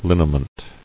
[lin·i·ment]